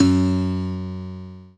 PIANO5-04.wav